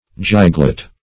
Giglot \Gig"lot\, Giglet \Gig"let\, n. [Cf. Icel. gikkr a pert,
giglet.mp3